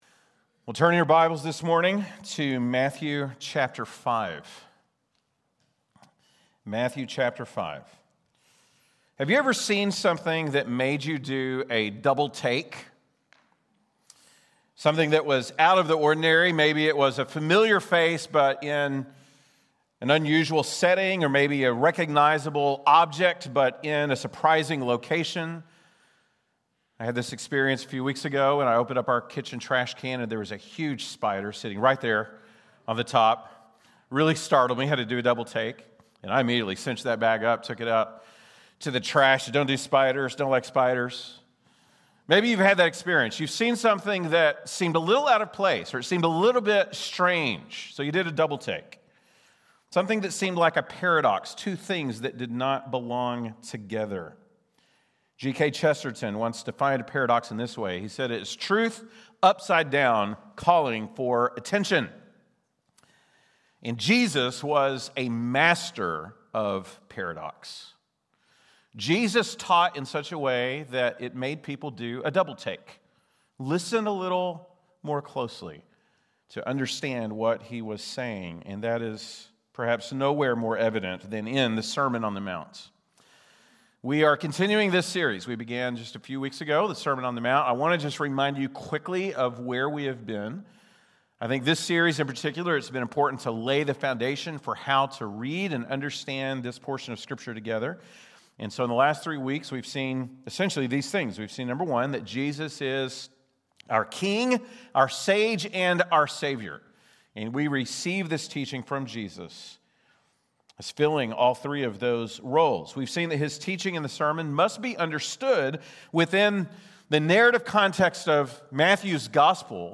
October 26, 2025 (Sunday Morning)